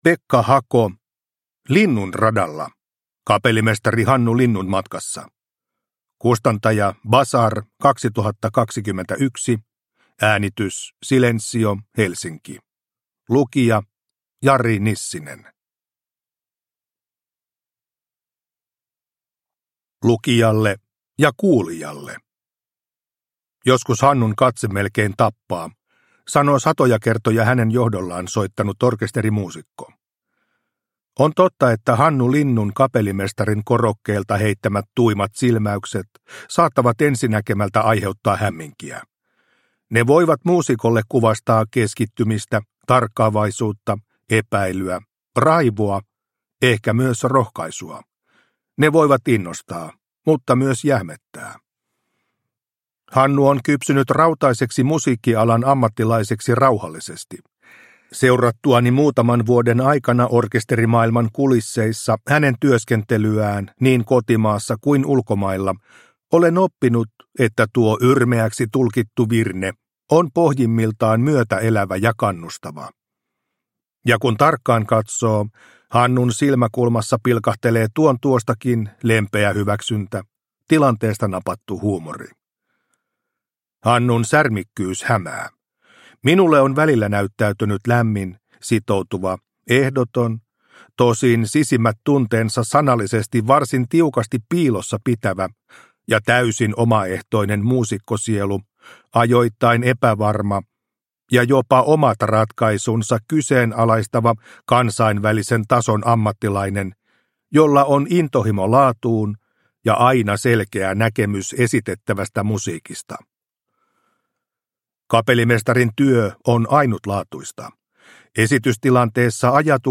Linnunradalla – Ljudbok – Laddas ner